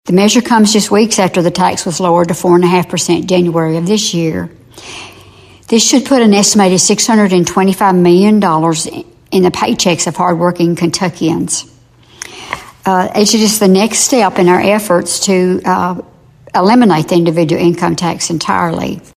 click to download audioFifth District State Representative Mary Beth Imes says tax reform will put money back in the pockets of Kentuckians.